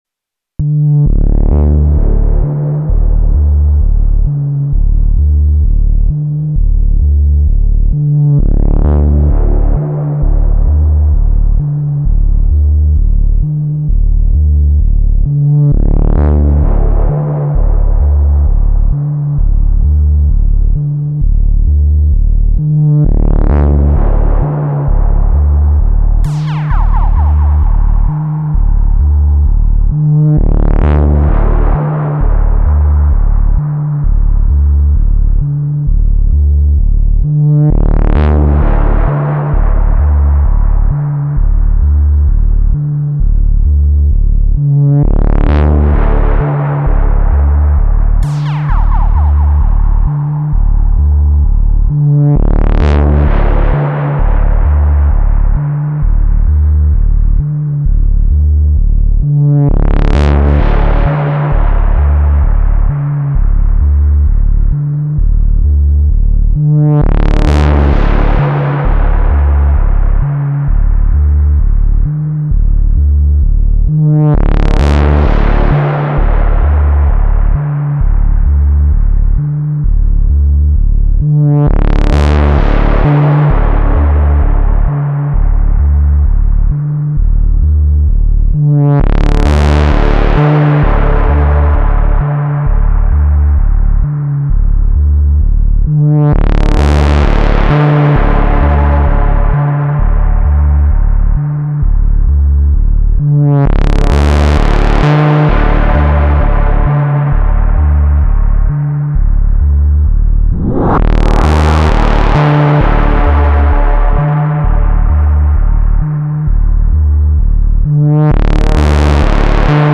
Nothing really beats the snap and crispness of the 808 snare–it’s brisk, baby, so that’s my snare sample along with using the stock rimshot as a background to it. Pads are samples on 5, 6, and 7, otherwise rest is all stock Rytm engines.